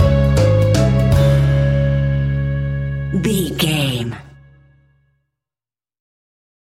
Aeolian/Minor
C#
childlike
happy
kids piano